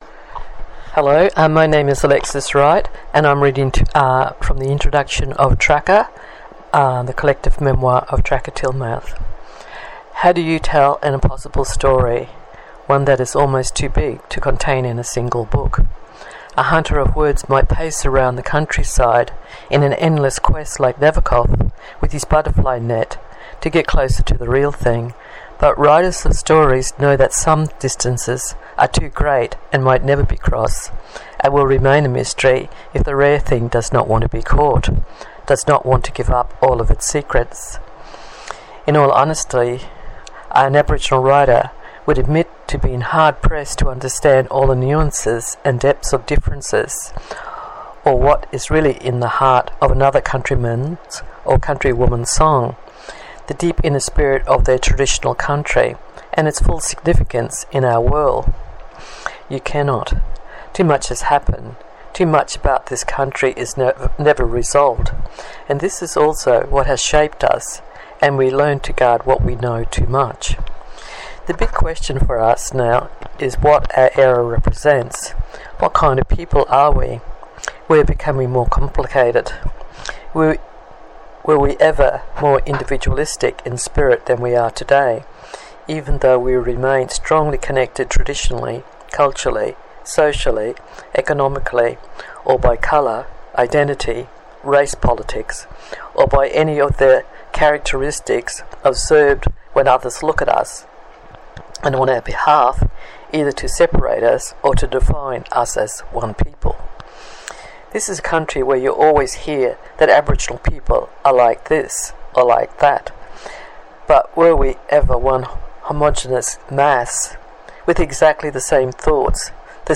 Audiobook sample
Alexis-Wright-reading.m4a